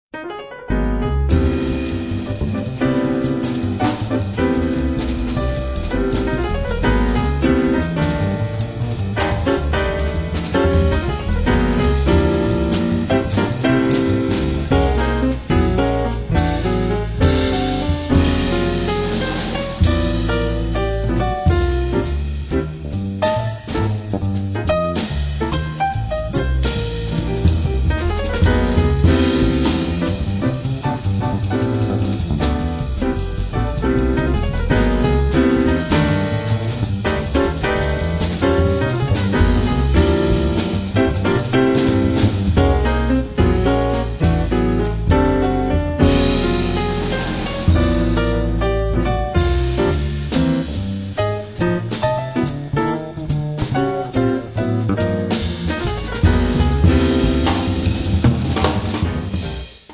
pianoforte
contrabbasso
batteria
con ampi e travolgenti cambi metrici